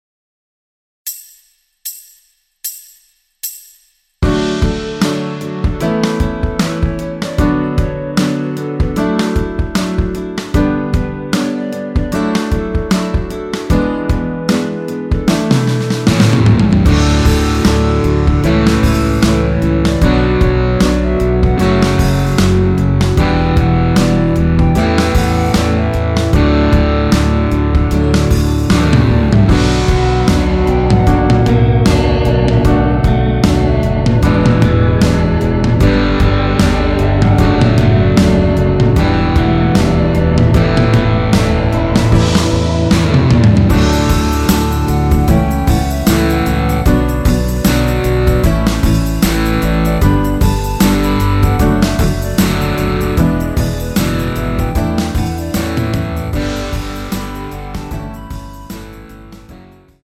원키에서(-3)내린 멜로디 포함된 MR입니다.
앞부분30초, 뒷부분30초씩 편집해서 올려 드리고 있습니다.